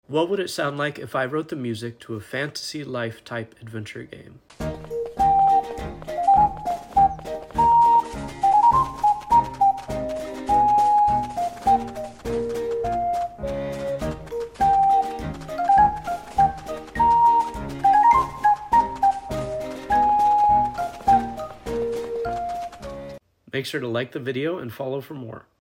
Writing music for a cute fantasy adventure game.